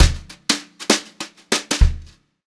200JZFILL2-L.wav